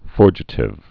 (fôrjĭ-tĭv)